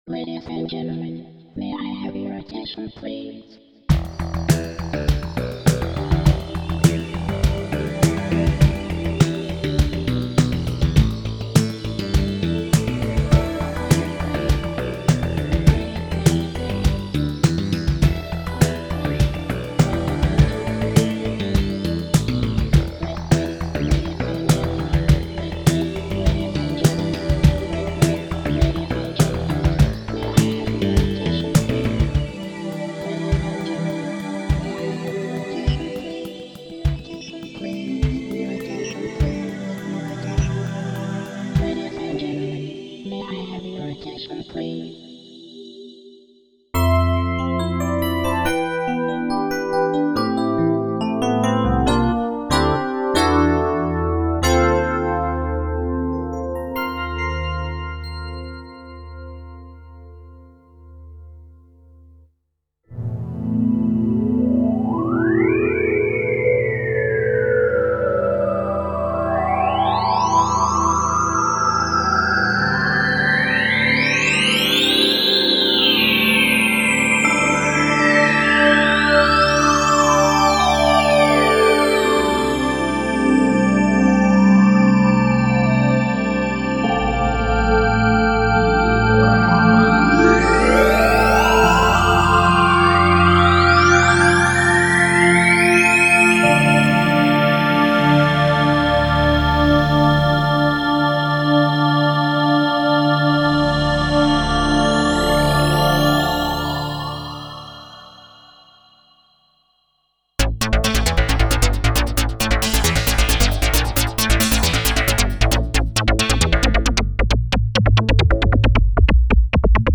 :play Factory Demos (15 Songs):